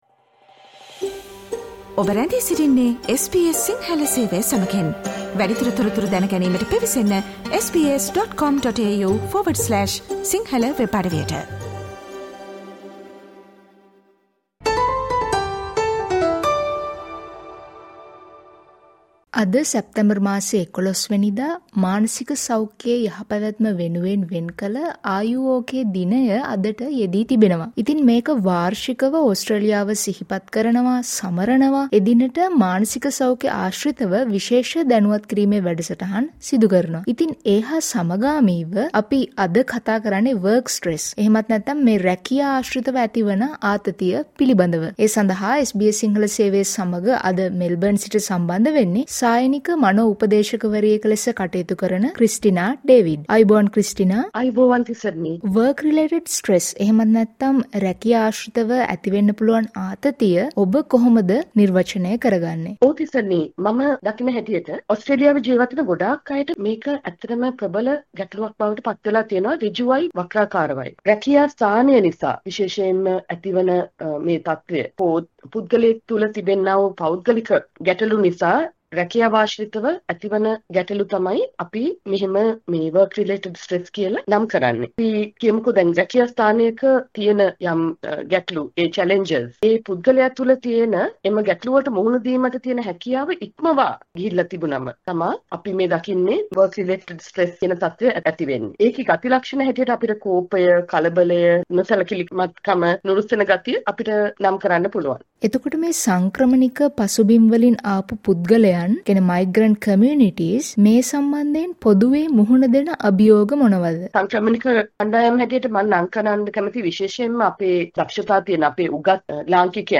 SBS සිංහල සේවය කළ සාකච්ඡාව